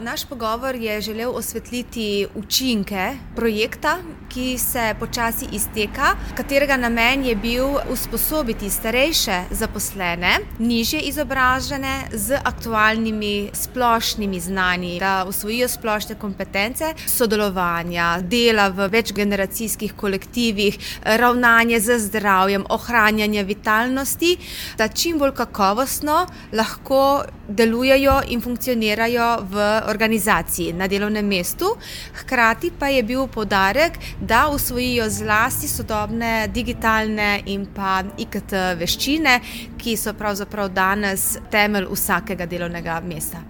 (- pripeta tonska izjava M. Mazzinija)